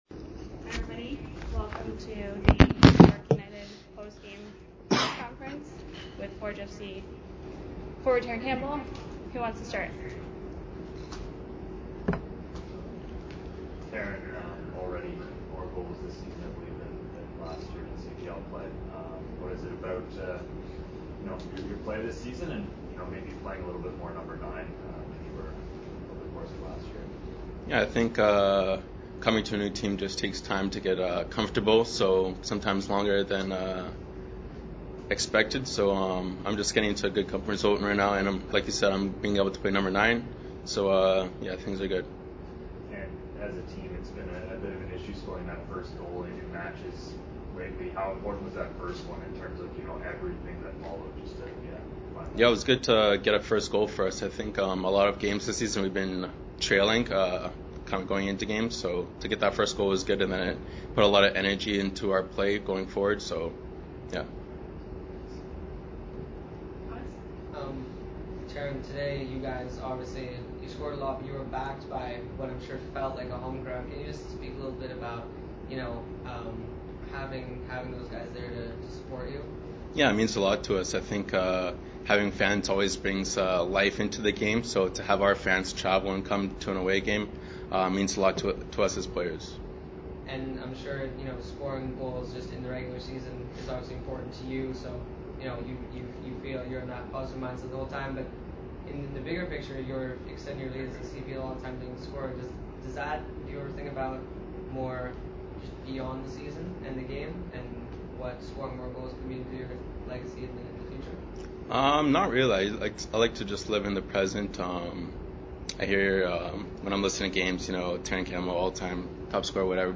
(garbled question is repeated clearer but close to mine) Was this a turnaround game for your team to start the second half of the season?